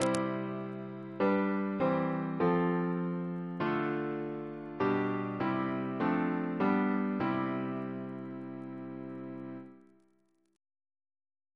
Single chant in C Composer: John Alcock (1715-1806), Organist of Lichfield Cathedral Reference psalters: OCB: 193